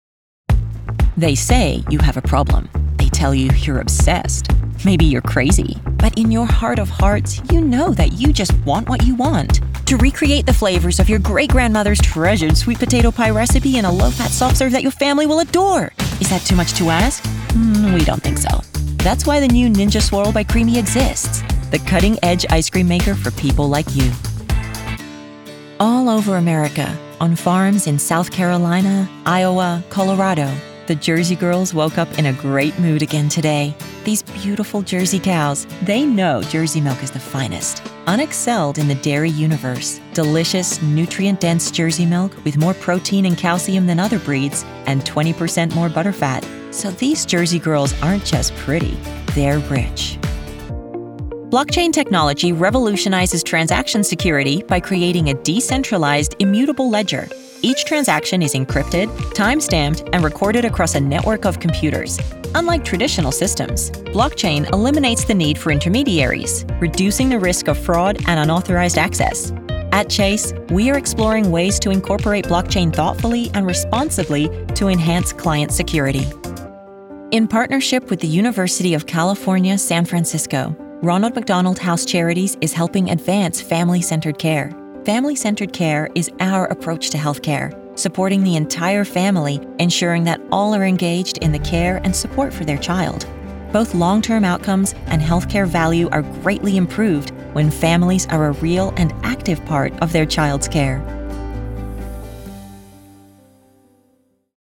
Female
I'm a trained actor with a grounded, connected sound.
Corporate
Words that describe my voice are Relatable, Conversational, Versatile.